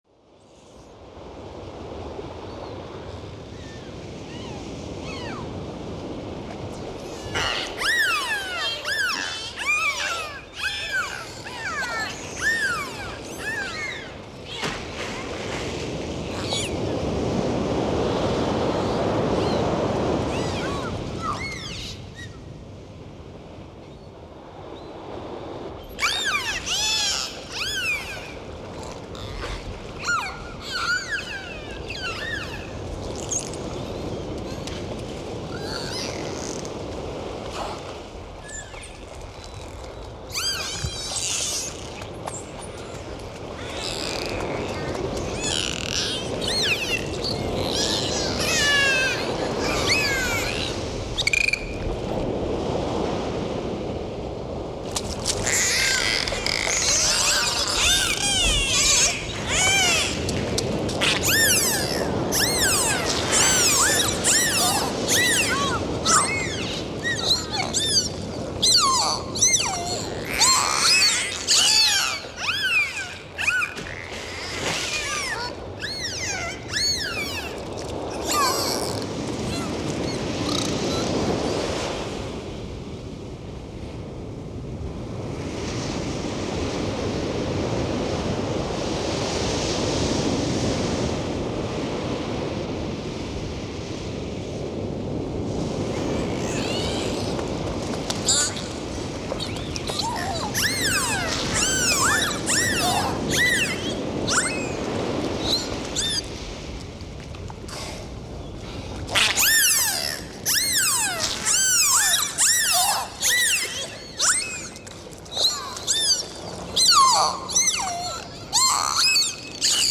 3D spatial surround sound "Dolphins and the sea"
3D Spatial Sounds